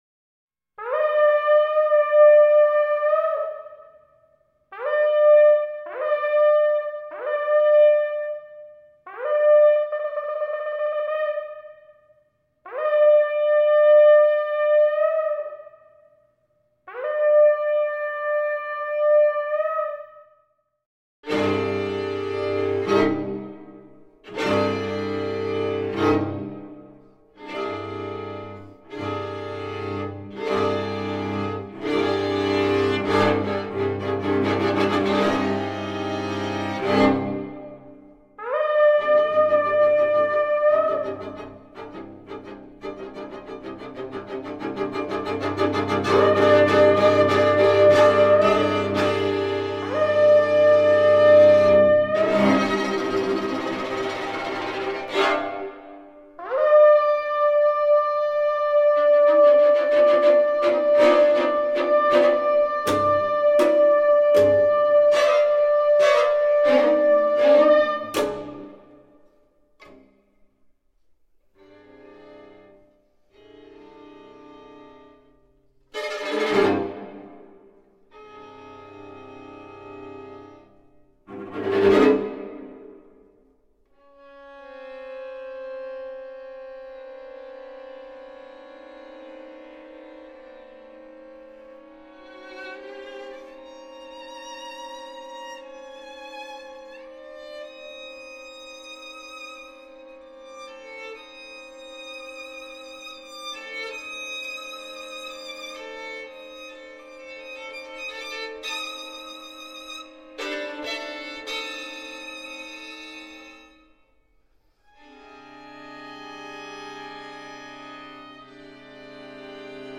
Instrumentation: String Quartet and electronics